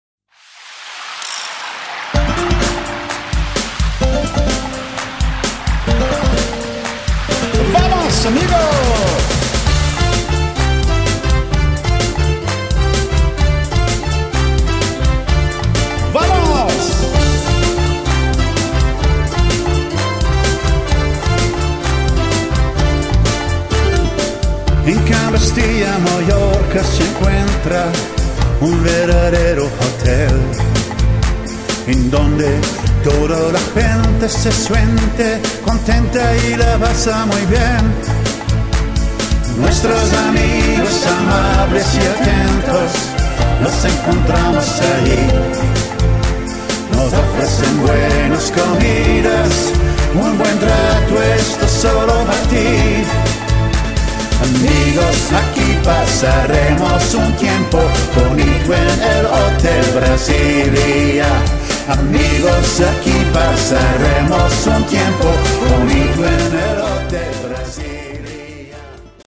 5-Mann Tanzorchester mit Sängerin für gehobene Ansprüche.
Die Lieder wurden live, direkt vom Mixer abgenommen, deshalb ist evtl. das Hallsignal rellativ hoch.